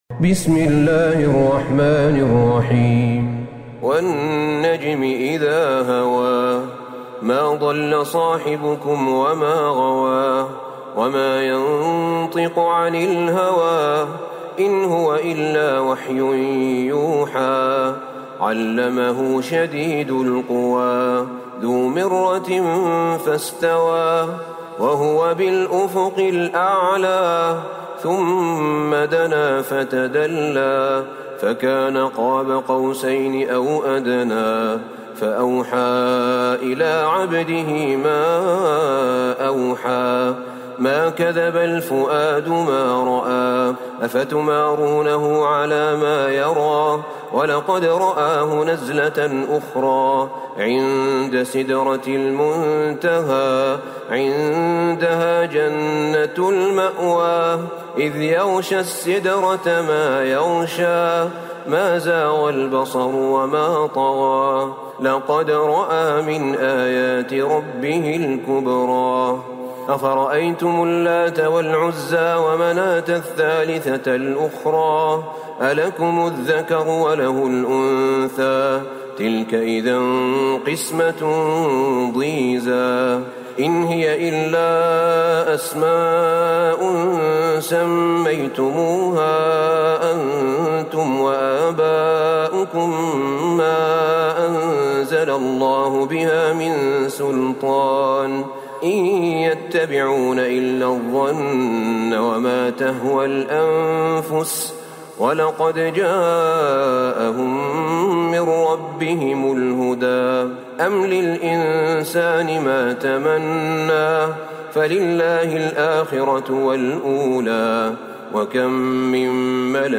سورة النجم Surat AnNajm > مصحف الشيخ أحمد بن طالب بن حميد من الحرم النبوي > المصحف - تلاوات الحرمين